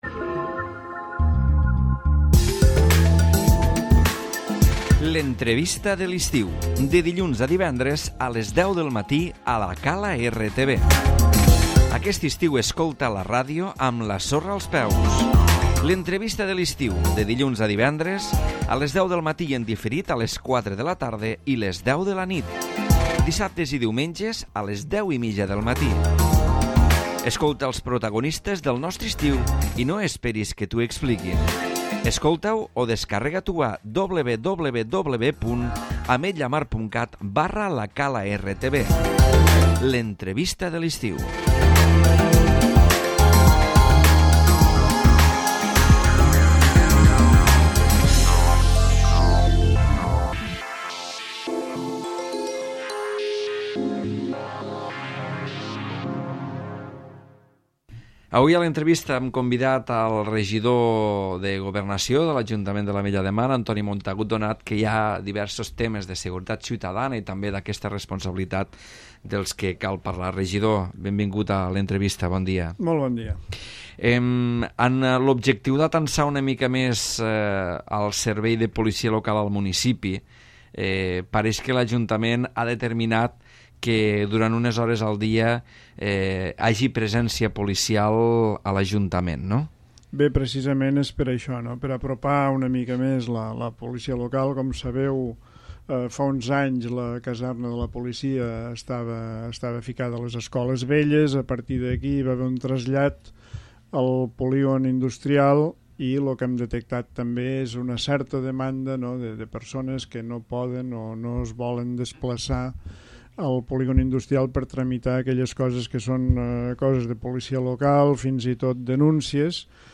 L'Entrevista
Un policia local farà tasques d'atenció al públic a l'Ajuntament. El Regidor de Governació de l'Ajuntament de l'Ametlla de Mar, Antoni Montagut, explica aquest nou servei que s'ha posat en funcionament los mesos d'estiu a l'Oficina d'Atenció al Ciutadà dels baixos de l'Ajuntament.